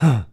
Male_Grunt_Curious_05.wav